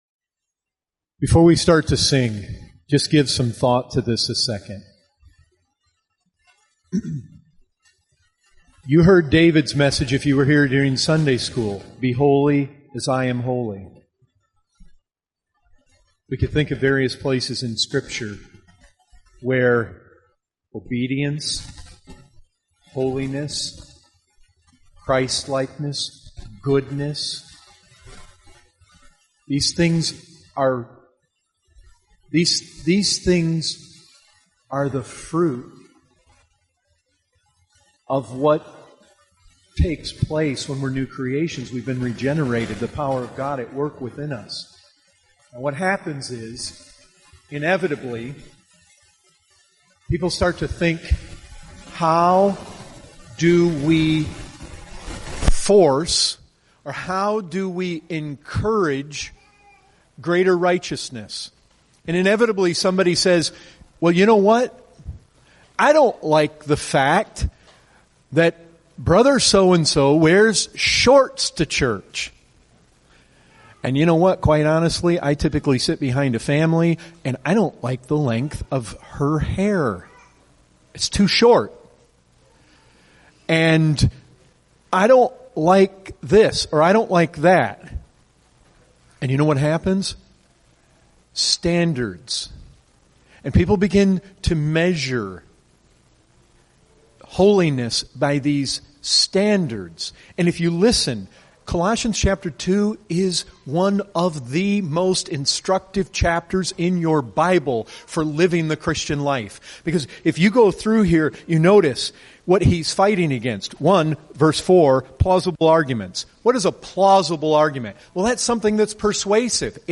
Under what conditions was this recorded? (Note: This exhortation was given after the Scripture reading of Colossians chapter 2, during our Sunday morning service.)